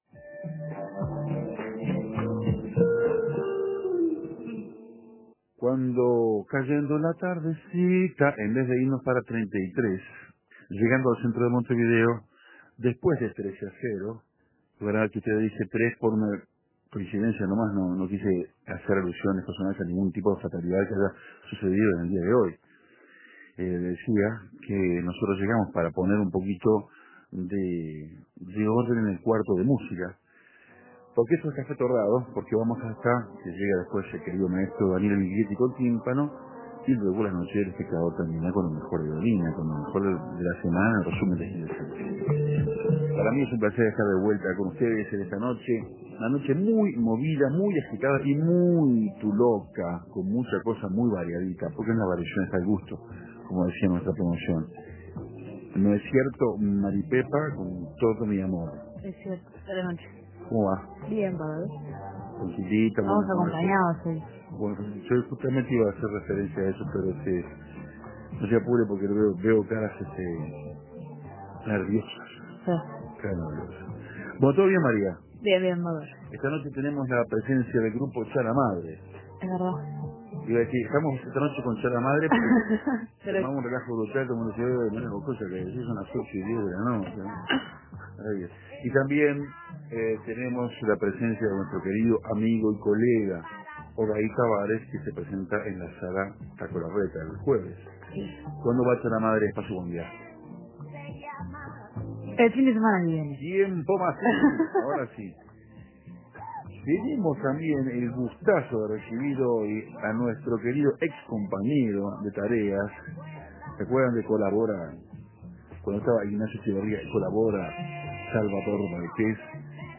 La fonoplatea acústica cocafetera estuvo muy concurrida.